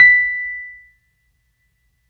Index of /90_sSampleCDs/Keyboards of The 60's and 70's - CD2/PNO_E.Grand/PNO_E.Grand